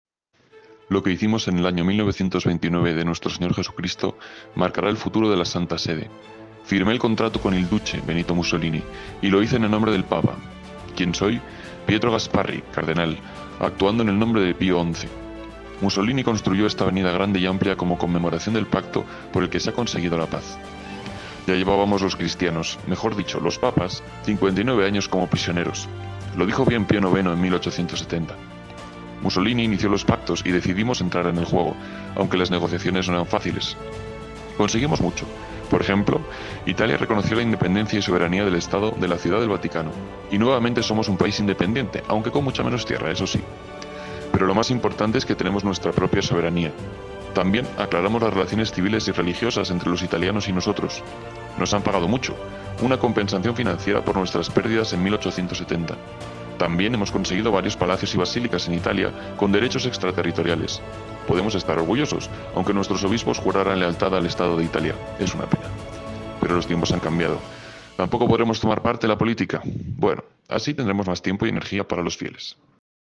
Pietro Gasparri, cardenal de Pio XI, habla de los Pactos de Letrán con Mussolini